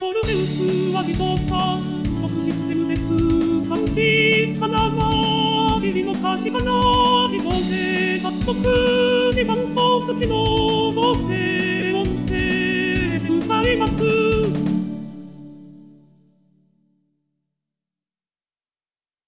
自動で作曲し、伴奏つきの
合成音声で歌います。